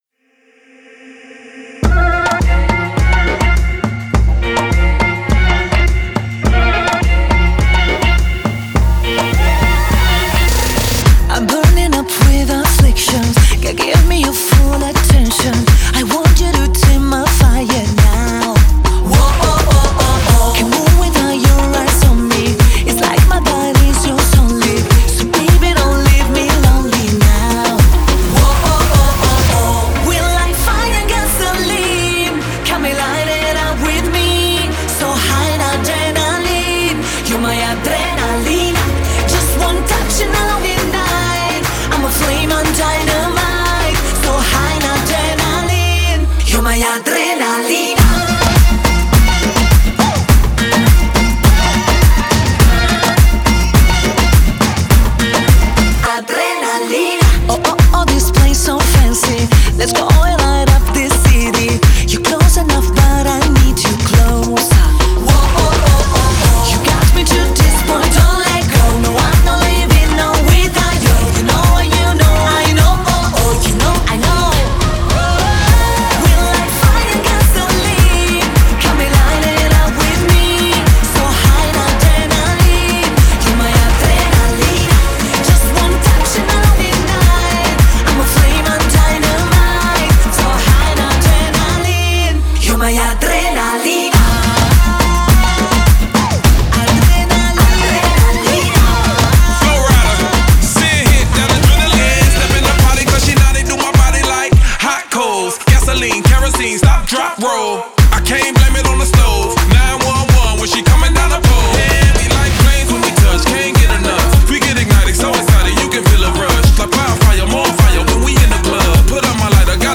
энергичная поп-песня
благодаря своему ритмичному биту и заразительным мелодиям.